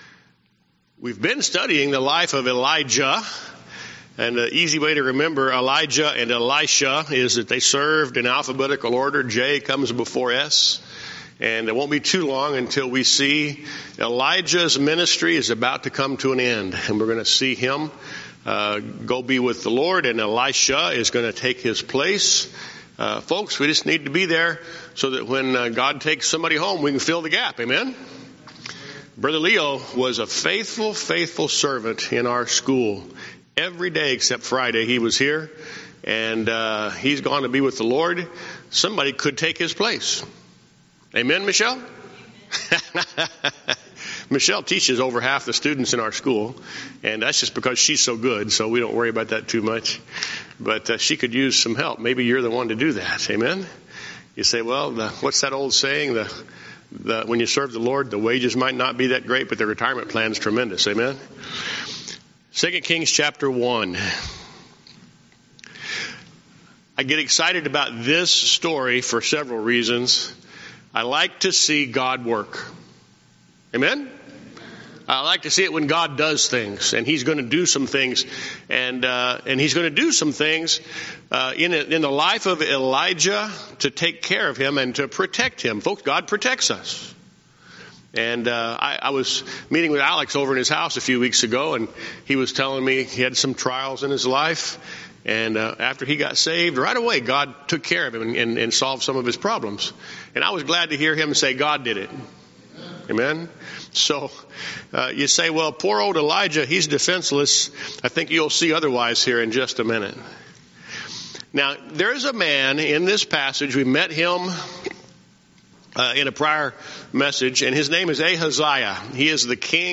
Sermon Recordings
Guest Speaker